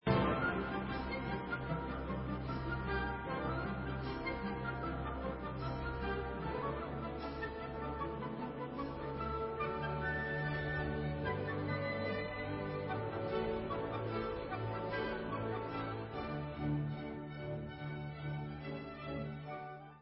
C dur (Allegro vivace) /Srbské kolo